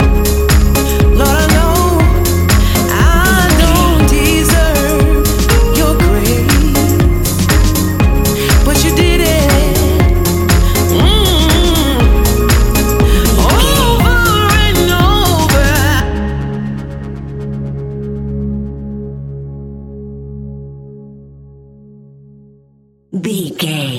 Ionian/Major
C♭
house
electro dance
synths
techno
trance